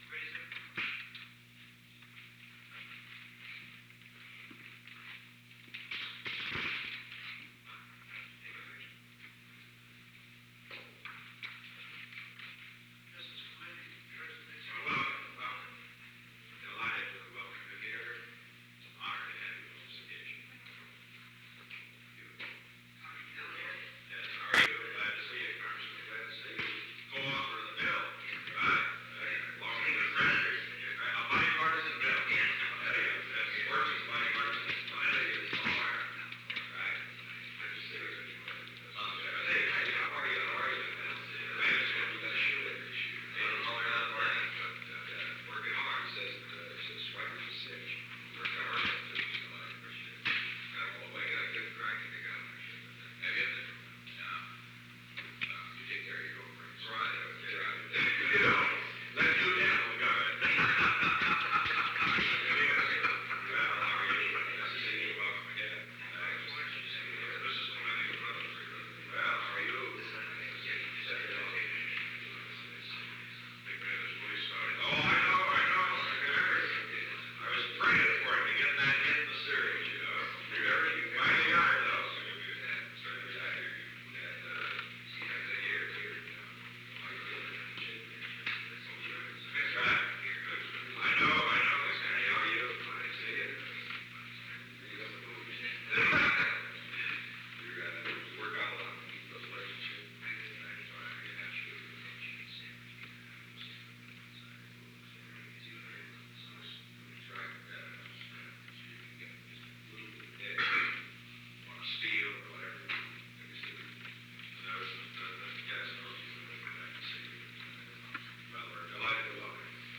Conversation No. 917-25 Date: May 14, 1973 Time: 12:04 pm - 12:19 pm Location: Oval Office The President met with an unknown man.
Members of the press were present at the beginning of the meeting.